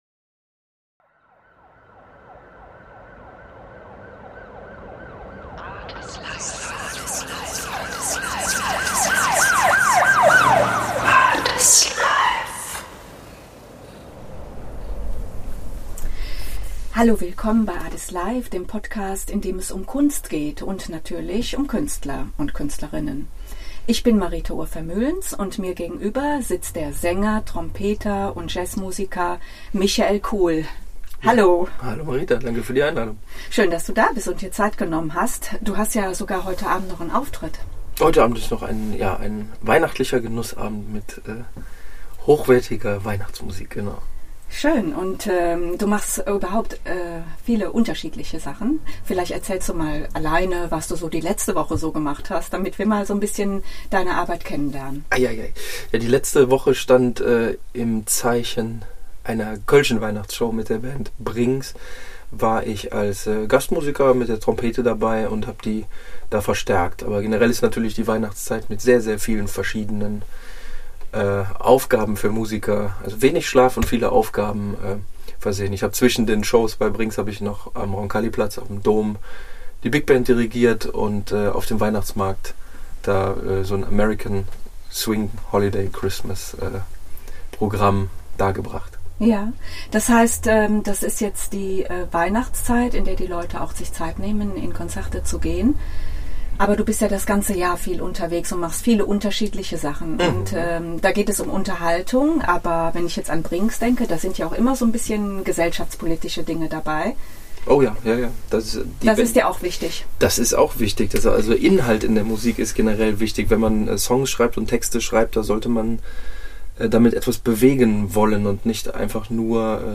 Ein Gespräch über Musik und das Leben.